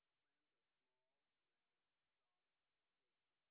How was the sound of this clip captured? sp21_white_snr0.wav